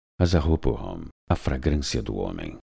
Masculino
Voz Padrão - Grave 00:02